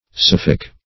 Search Result for " sophic" : The Collaborative International Dictionary of English v.0.48: Sophic \Soph"ic\, Sophical \Soph"ic*al\, a. [Gr.